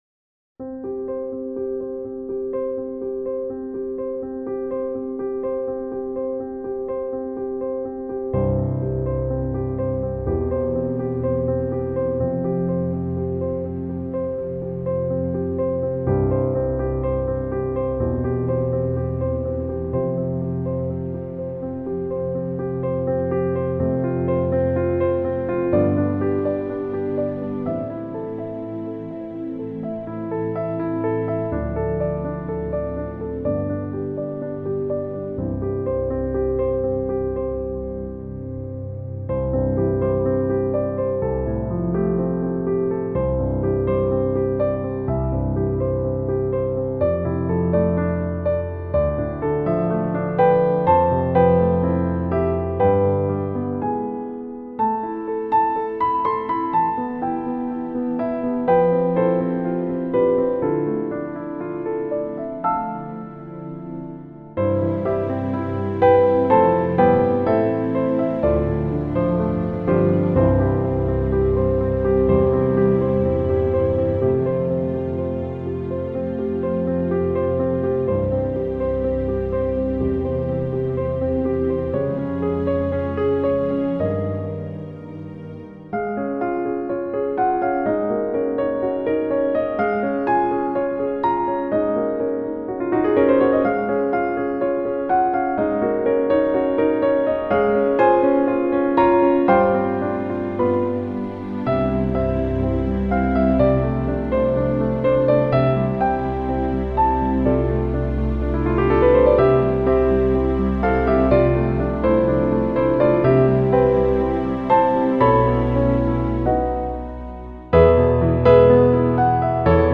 A service for 2nd May 2021